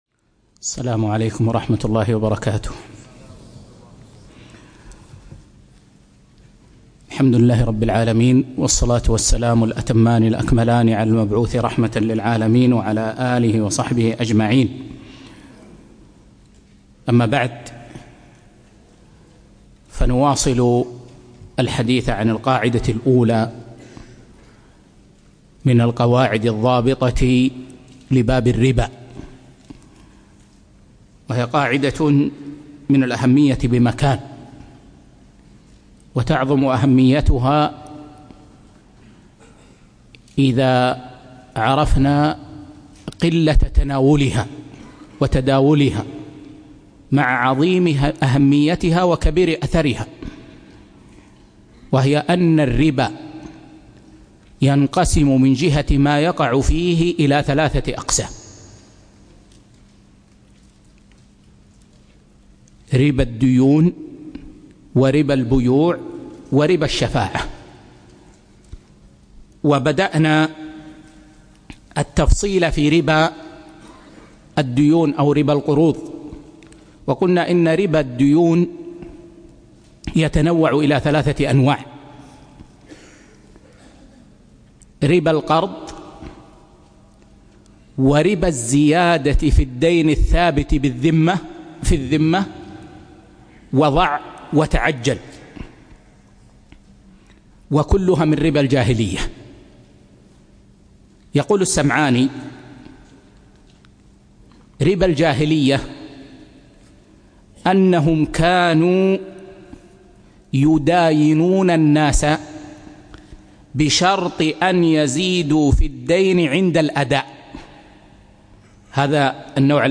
2- فقه المعاملات المالية (2) - الدرس الثاني